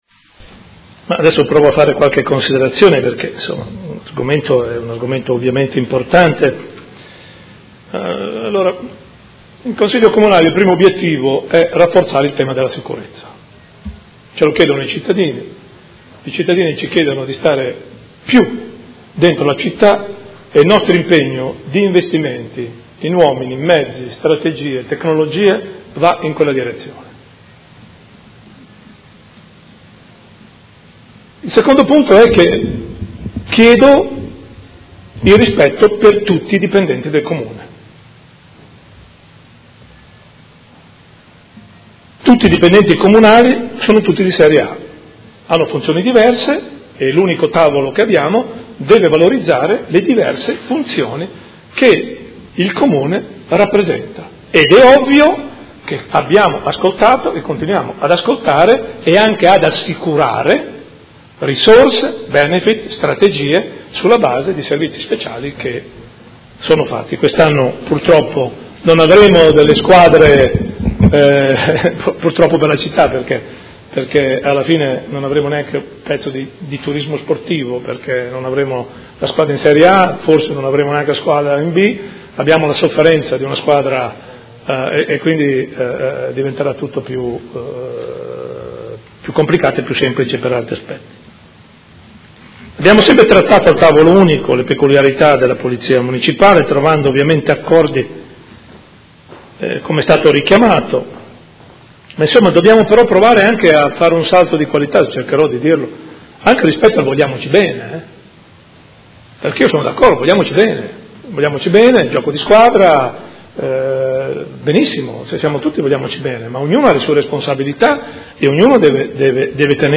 Gian Carlo Muzzarelli — Sito Audio Consiglio Comunale
Seduta del 7 luglio. Interrogazione del Gruppo Movimento Cinque Stelle avente per oggetto: Chiarezza sulla situazione all’interno del Corpo di Polizia Municipale. Replica del sindaco e comunicazione sulle nomine